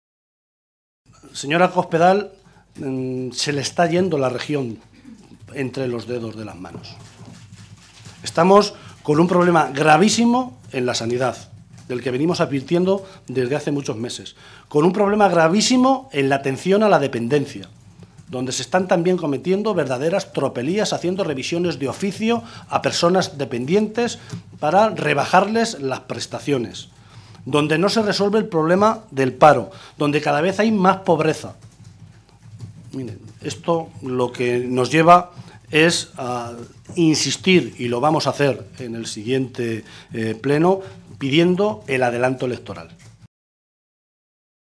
José Luis Martínez Guijarro, portavoz del Grupo Parlametario Socialista
Cortes de audio de la rueda de prensa